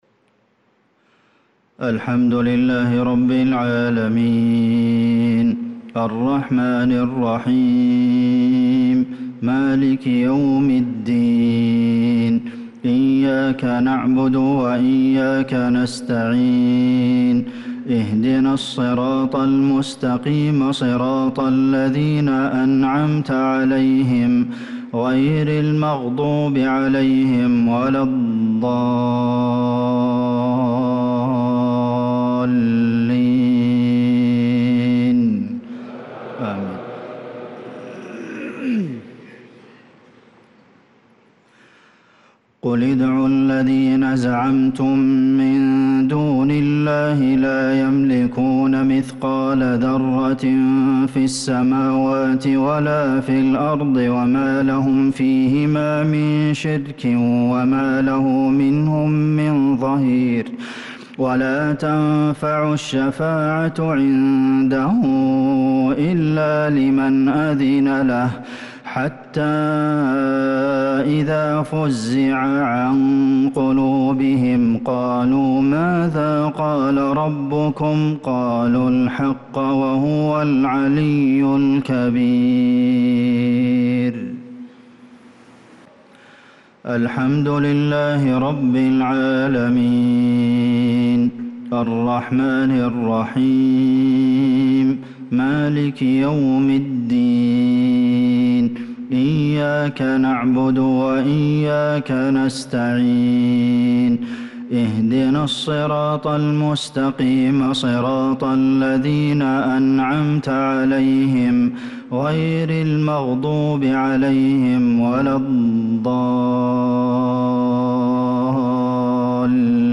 صلاة المغرب للقارئ عبدالمحسن القاسم 29 محرم 1446 هـ
تِلَاوَات الْحَرَمَيْن .